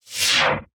Scifi Screen UI 7.wav